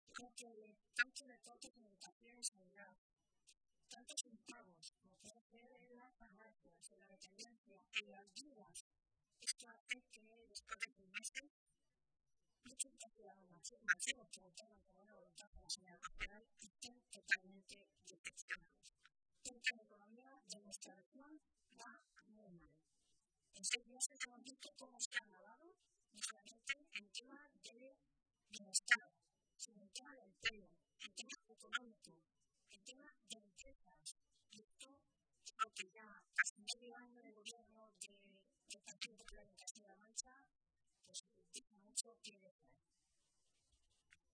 Milagros Tolón, portavoz de Empleo del Grupo Socialista
Cortes de audio de la rueda de prensa